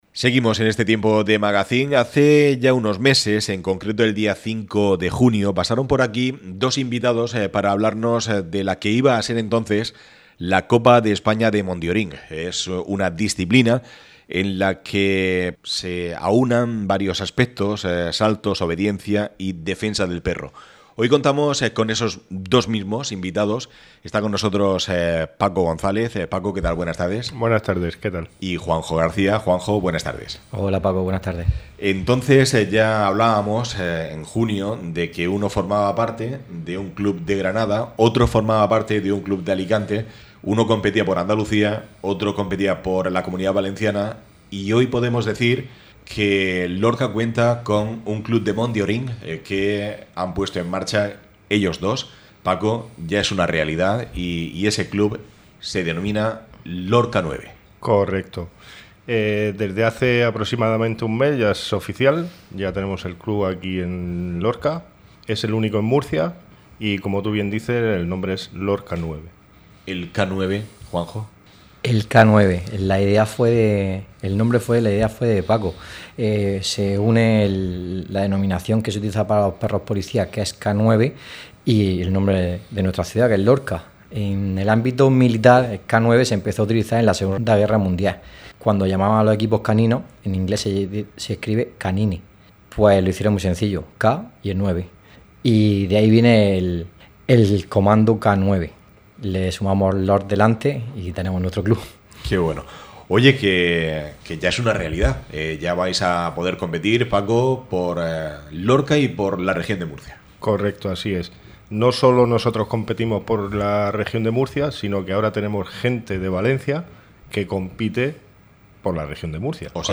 ÁREA LORCA RADIO. Deporte canino.